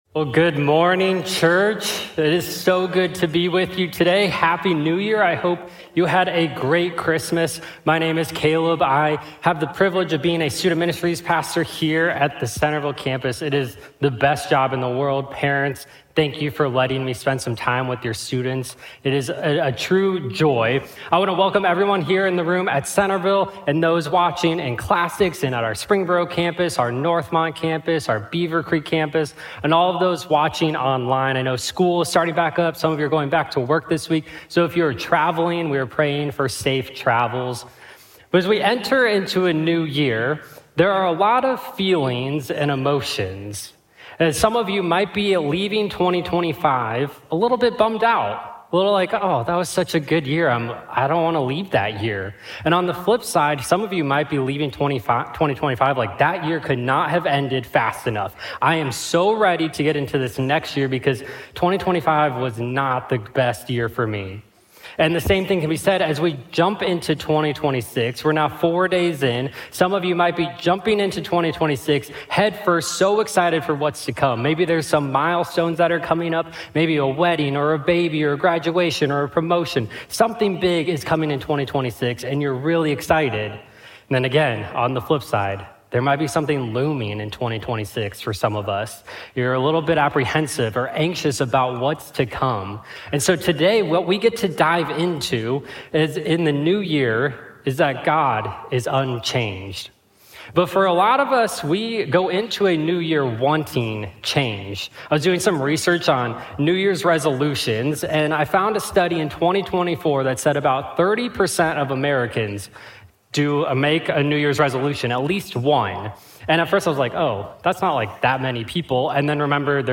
Nothing-Changes-God_SERMON.mp3